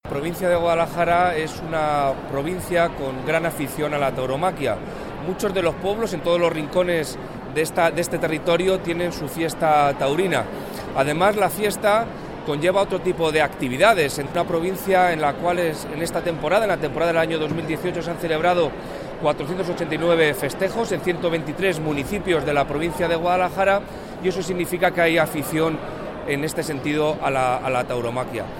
El delegado de la Junta en Guadalajara habla de la importancia de la tauromaquia en la provincia.